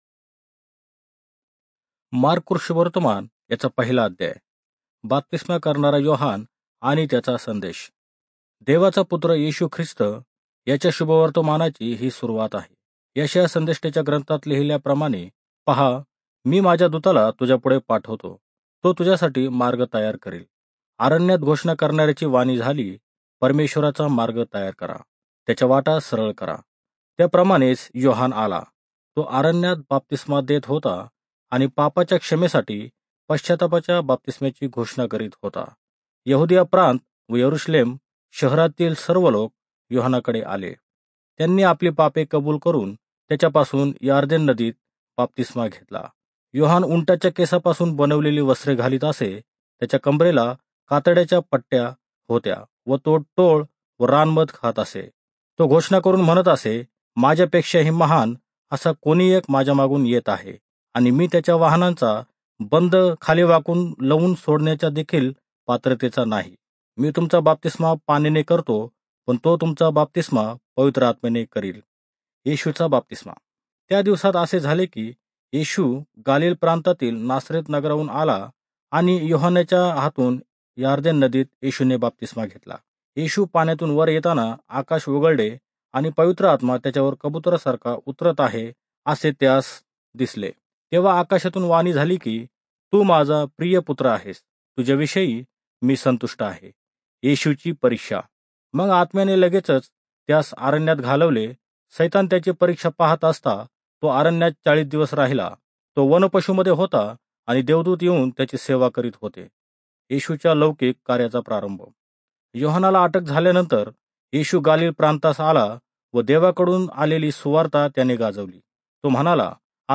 marathi-bible-09937-genesis-1.mp3
• You and others can listen to the Bible in Marathi being read aloud with the built in speaker
• Word for word narration
• Voice only Bible reading